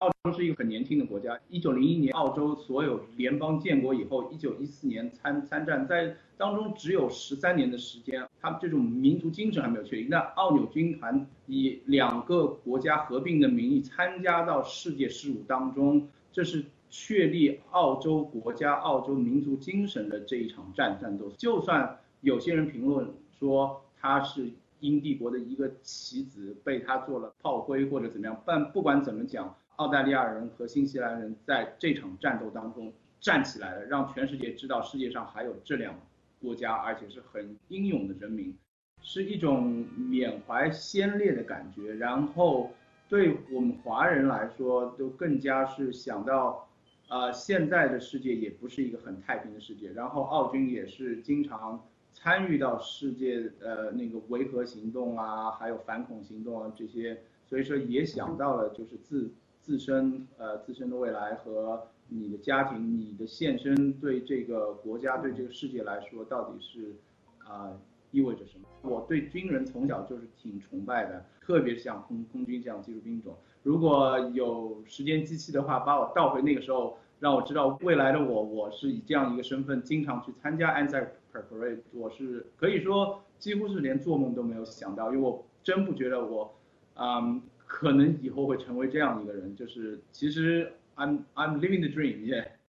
点击请听完整采访录音 你了解 ANZAC DAY 吗？